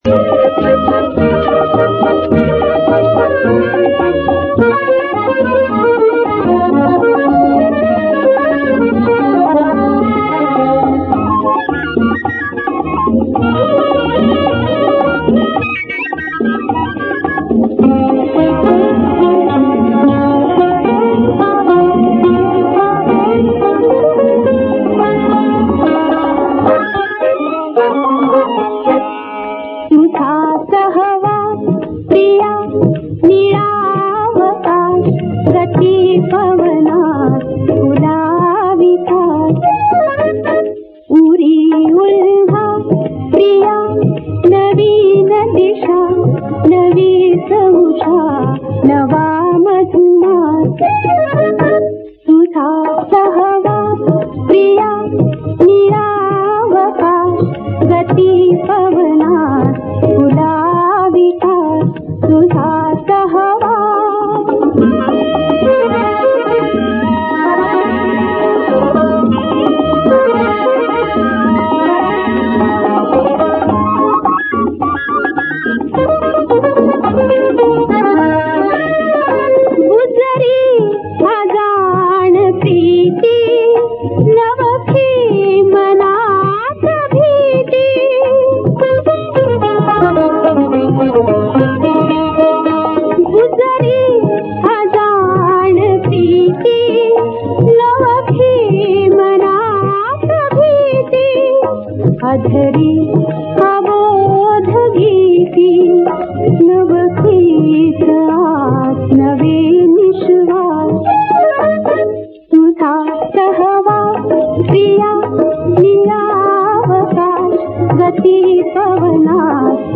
(Marathi)